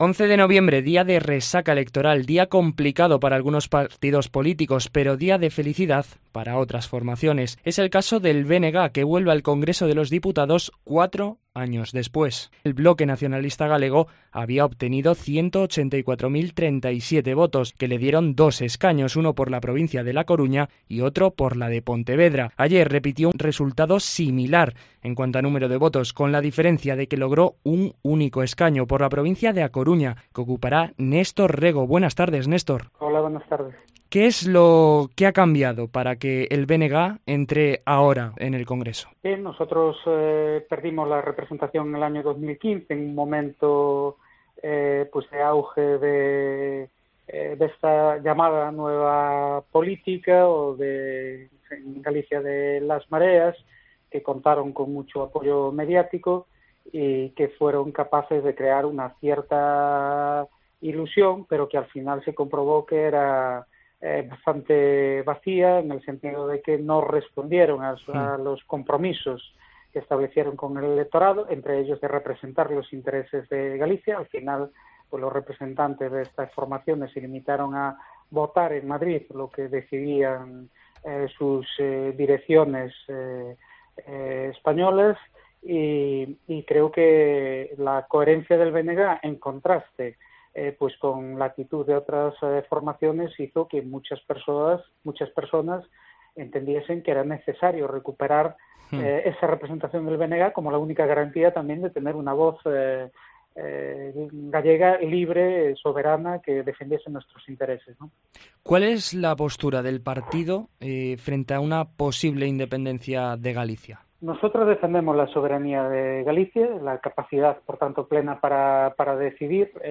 Nestor Rego, diputado del BNG, en COPE: “Defendemos el derecho de autodeterminación para todos los pueblos”
El nuevo diputado del Congreso de los Diputados habla en COPE sobre el papel que desempeñará el BNG durante esta nueva legislatura